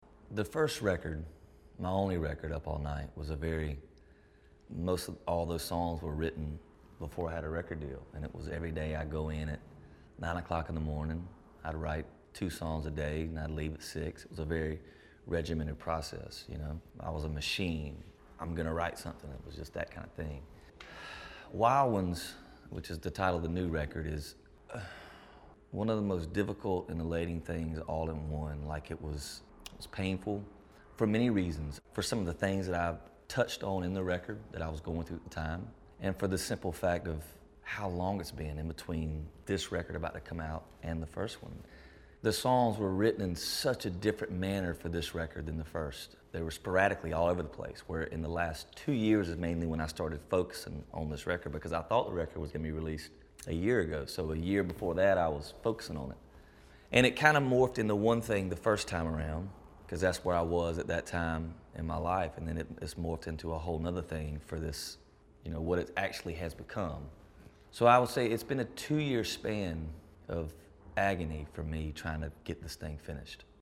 Audio / Kip Moore talks about the differences in making his previous album, Up All Night, and his new project, Wild Ones.